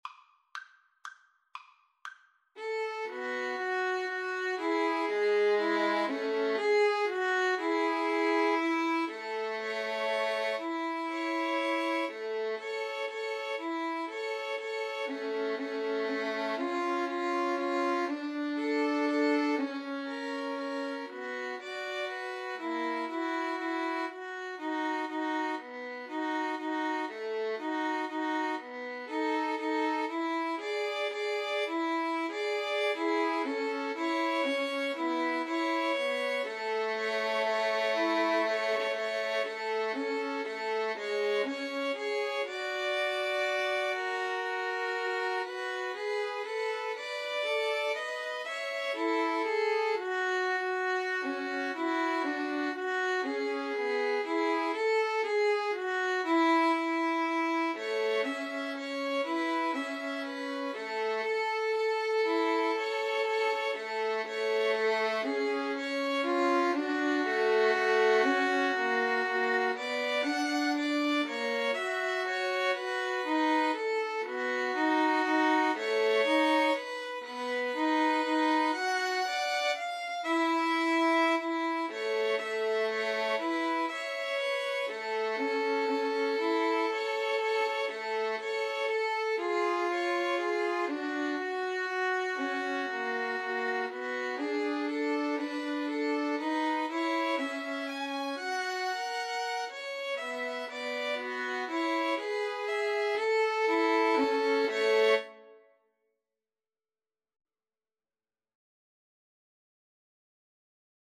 Free Sheet music for Violin Trio
A major (Sounding Pitch) (View more A major Music for Violin Trio )
= 120 Tempo di Valse = c. 120
3/4 (View more 3/4 Music)